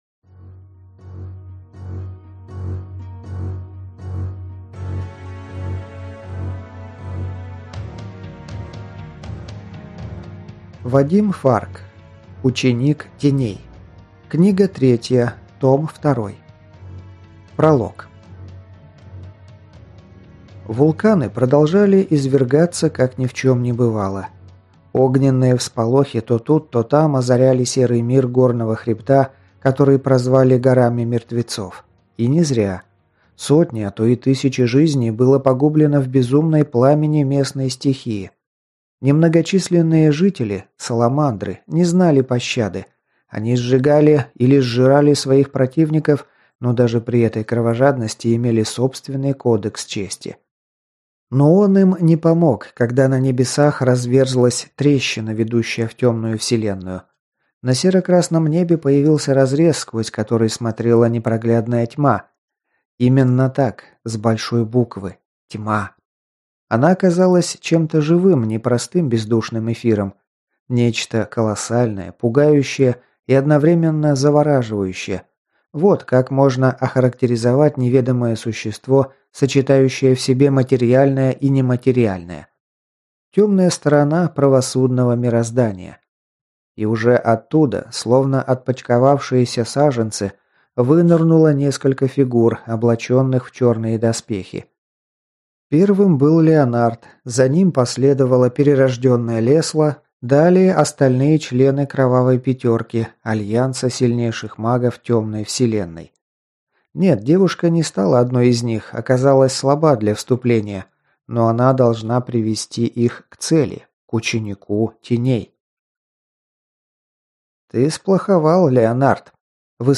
Аудиокнига Ученик Теней. Книга 3. Том 2 | Библиотека аудиокниг